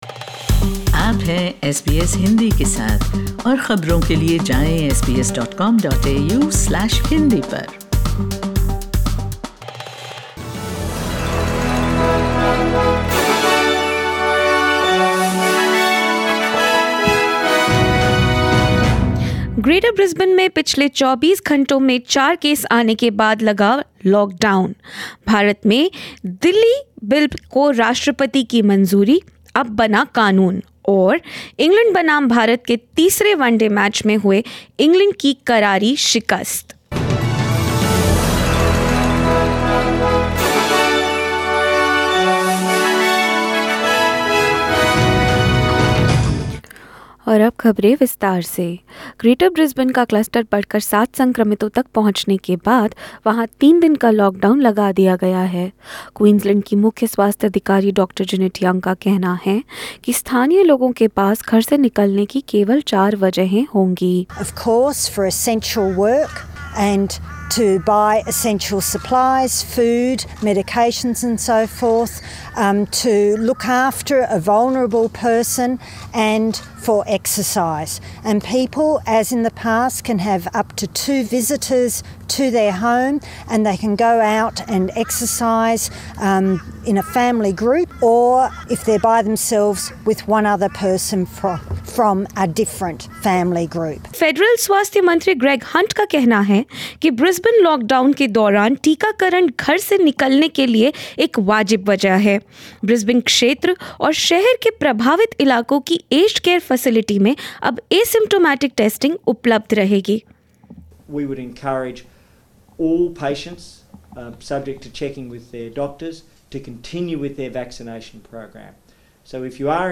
News in Hindi - Brisbane enters a three day lockdown